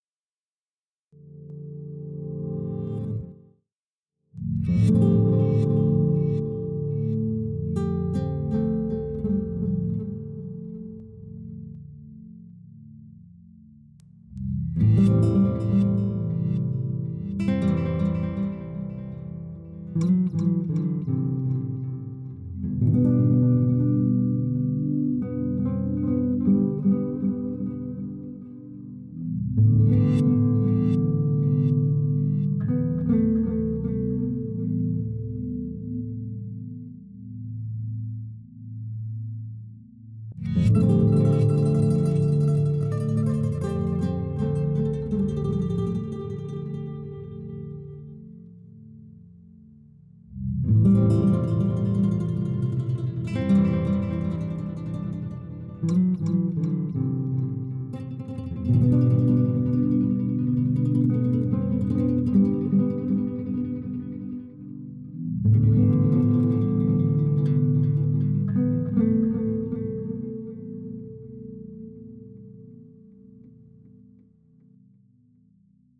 But for now this is a nice minimilist theme that sets the mood correctly.